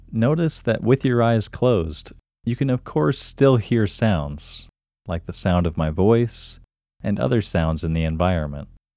QUIETNESS Male English 2
The-Quietness-Technique-Voice-Over-2.mp3